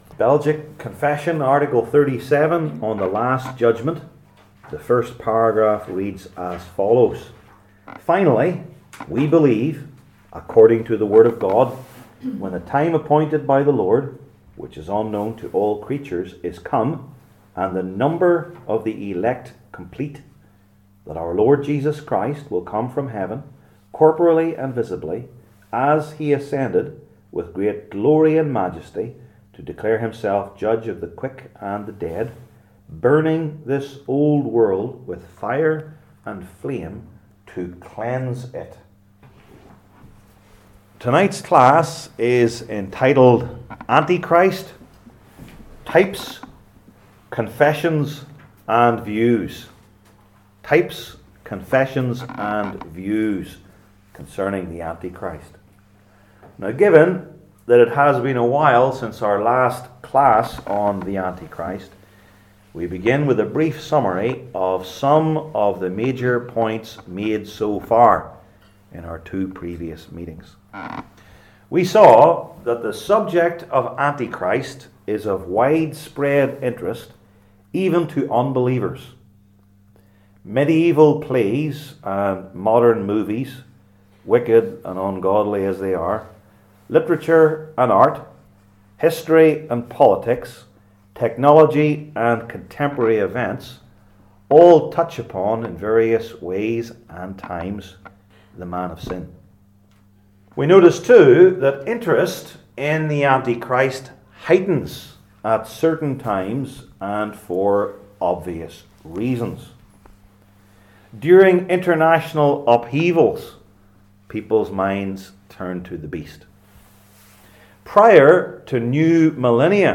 Passage: Revelation 13:1-9 Service Type: Belgic Confession Classes